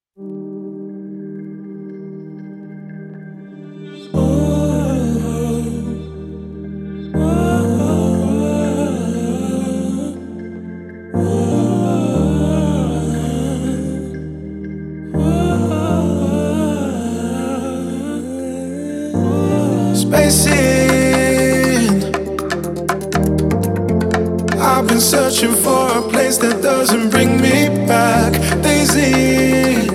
Жанр: R&B / Танцевальные / Соул